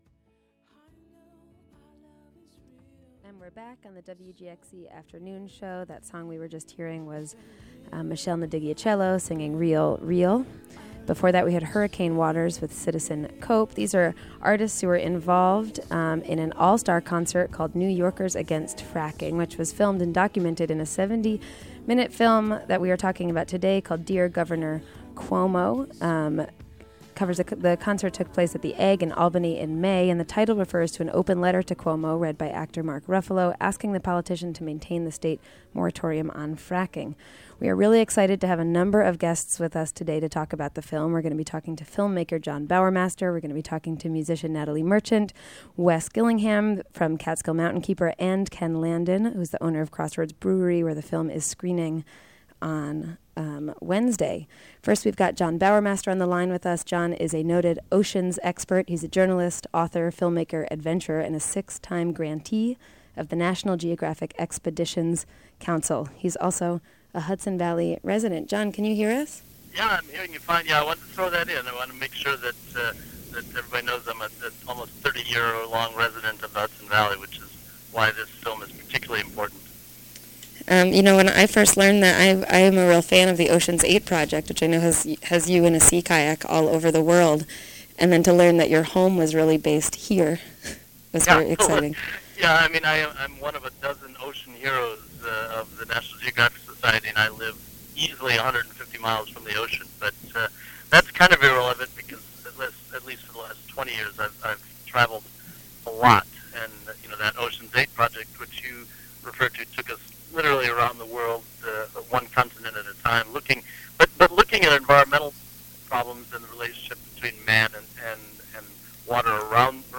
features a conversation about "Dear Governor Cuomo...